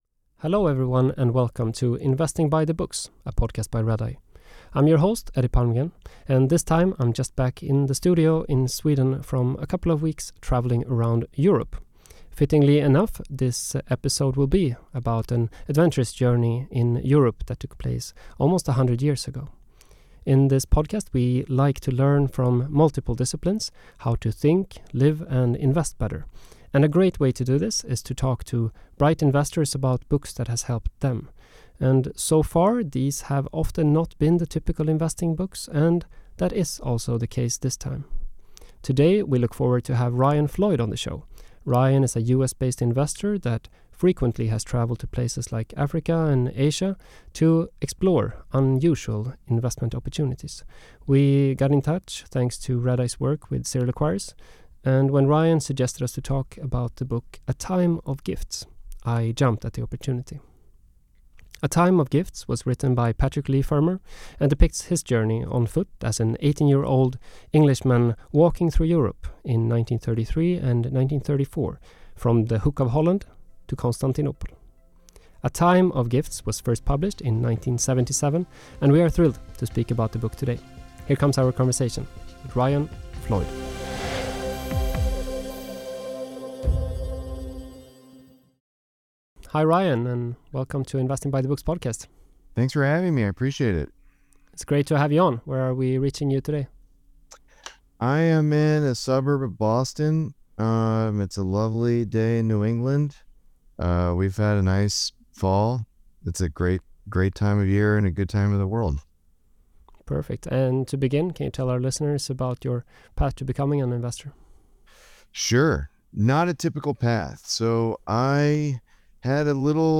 Bill Nygren On Why Modern Investing Requires Seeing Beyond The Balance Sheet 59:47 Play Pause 6d ago 59:47 Play Pause Play later Play later Lists Like Liked 59:47 Episode Overview In this wide-ranging conversation, legendary investor Bill Nygren discusses how value investing has evolved since the 1980s—and why the traditional valuation metrics many investors still rely on no longer adequately measure a company’s true worth.